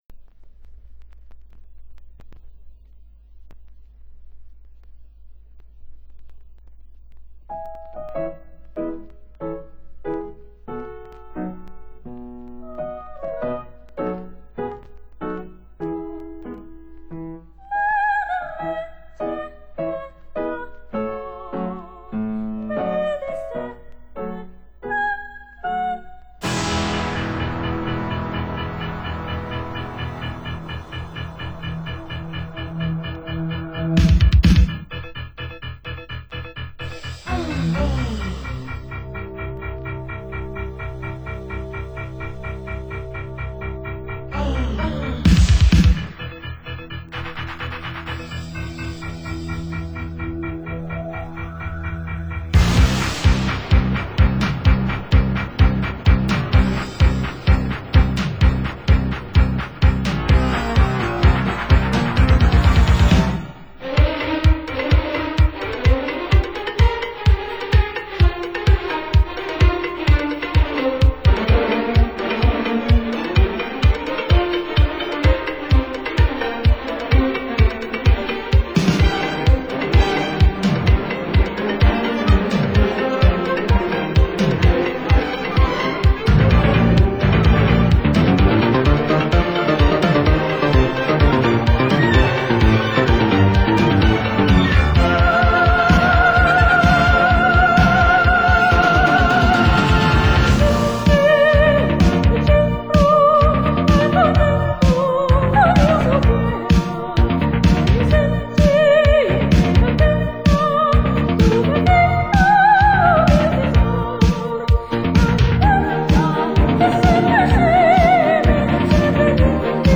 黑膠轉WAV轉APE
曲長十幾分鐘低音質 32K